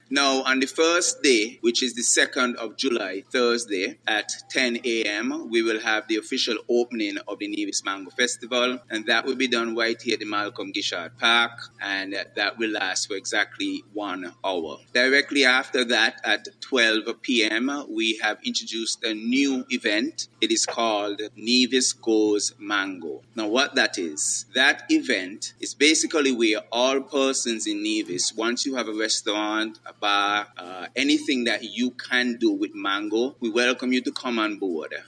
The information was revealed during a Press Launch on Friday, April 24th at the Malcolm Guishard Recreational Park at Pinneys.